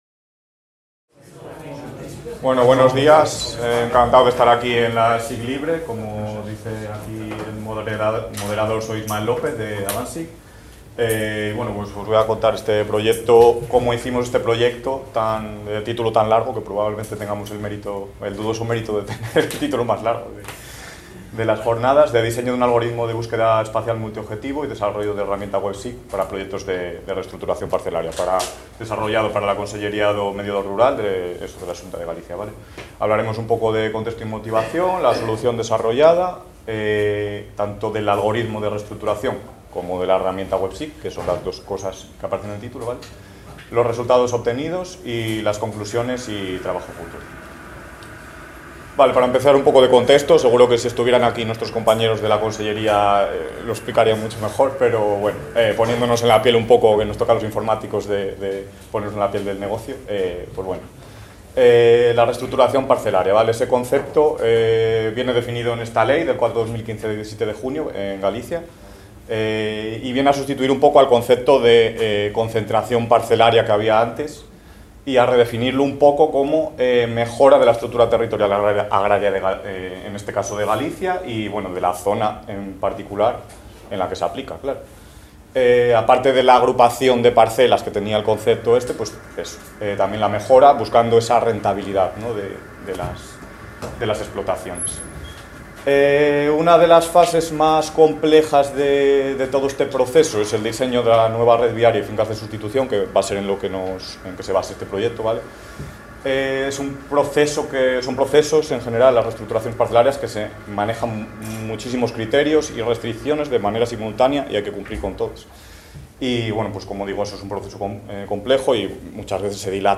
en el marc de les 18enes Jornades de SIG Lliure 2025 organitzades pel SIGTE de la Universitat de Girona. S'explica el projecte de redisseny de parcel·les a Galícia. La solució desenvolupada aborda tant les necessitats específiques de reestructuració parcel·lària a zones agrícoles com a àrees forestals.